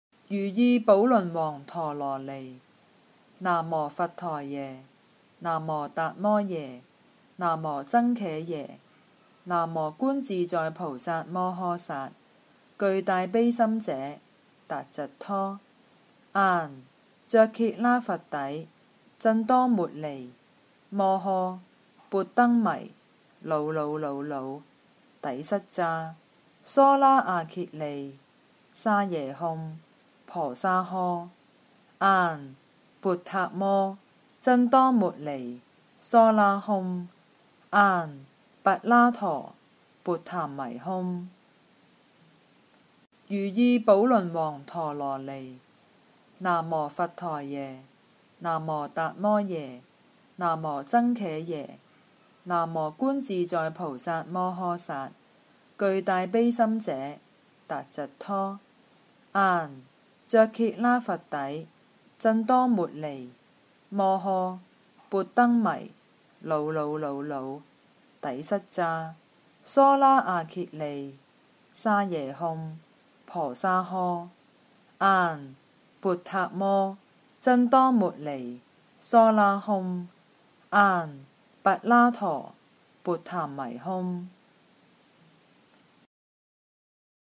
《如意宝罗王陀罗尼》经文教念粤语版